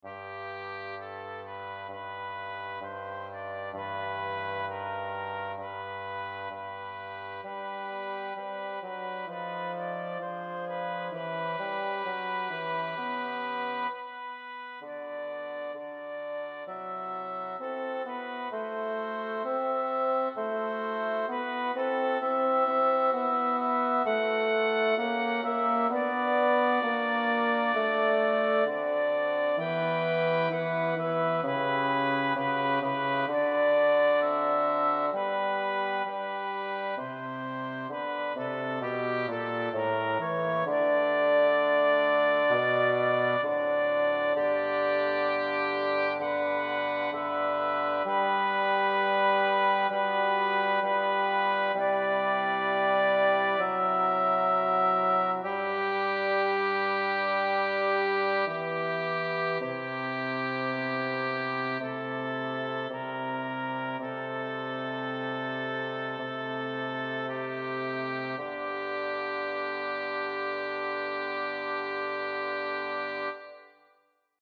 Bas 1: